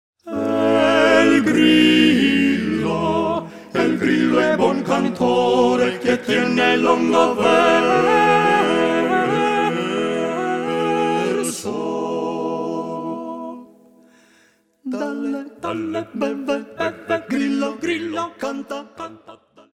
madrigals composed during the Renaissance
This is vocal music that belongs to the soul.